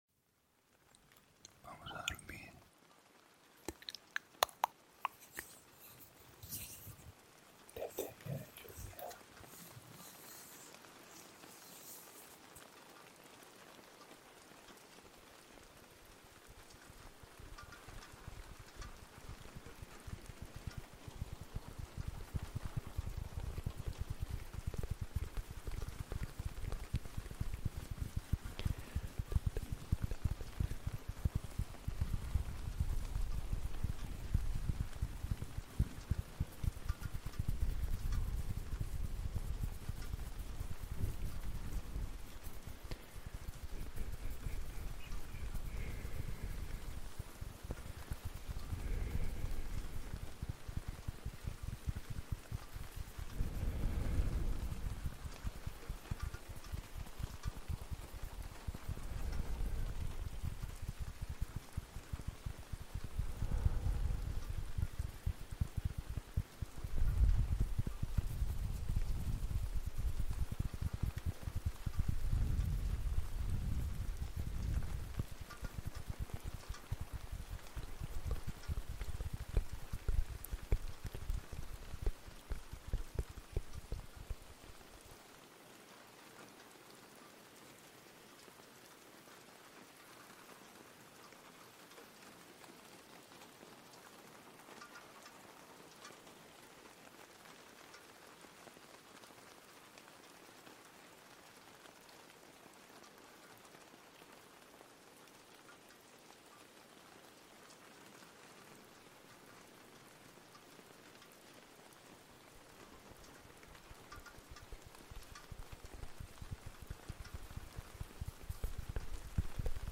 ASMR para dormir - Lluvia y manos ☔